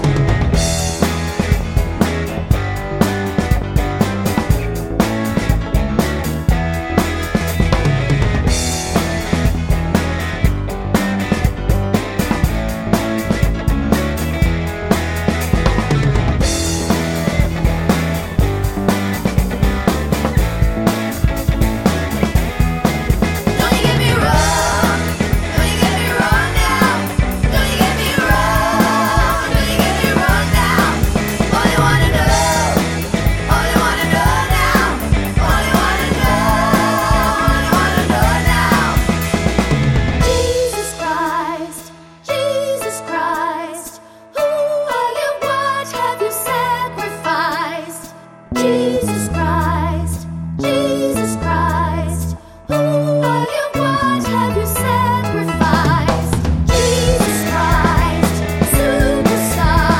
no Backing Vocals Musicals 4:06 Buy £1.50